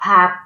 _ pagg